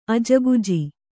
♪ ajaguji